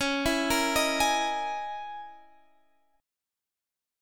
C#m6add9 chord